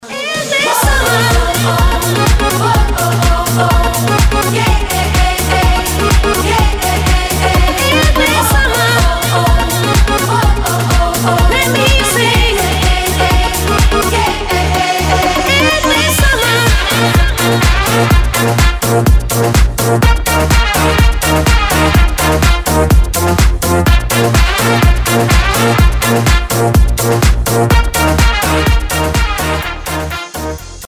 зажигательные
веселые
заводные
dance
Зажигательный рингтон!